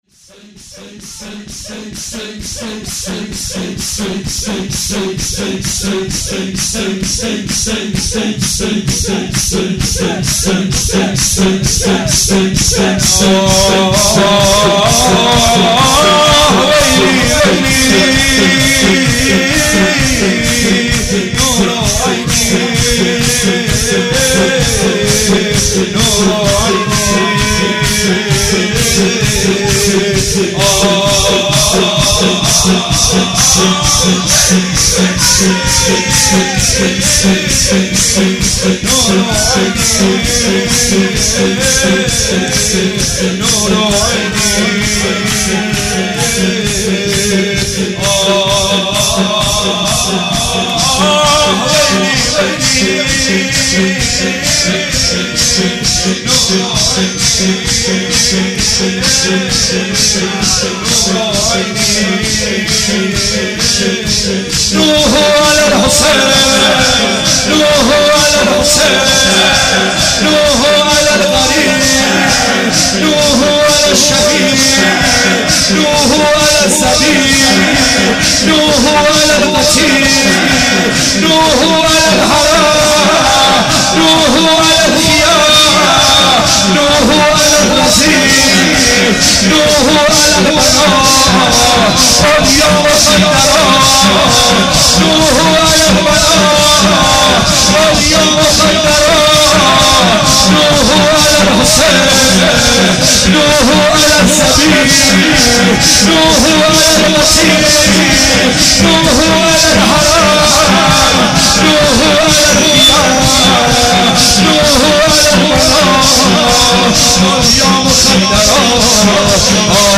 مناسبت : شهادت امام رضا علیه‌السلام
قالب : شور